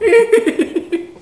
rire_05.wav